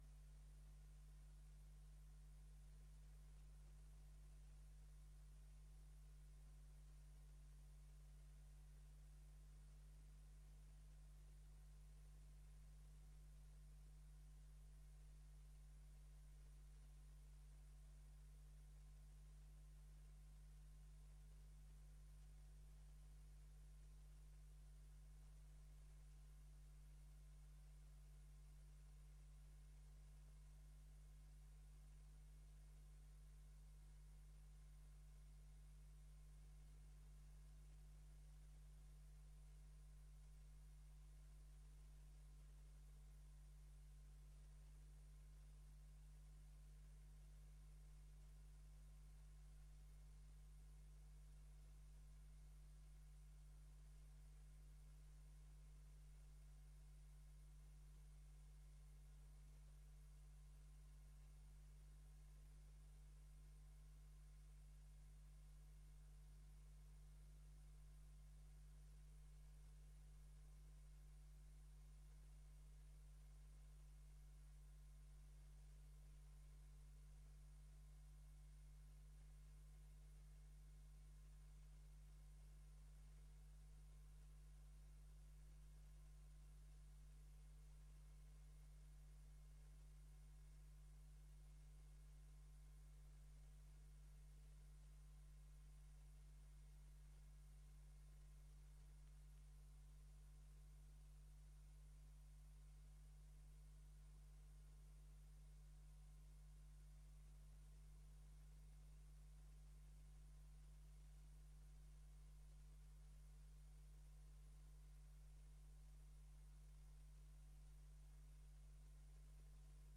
Gemeenteraad 22 januari 2024 20:00:00, Gemeente Dalfsen
Download de volledige audio van deze vergadering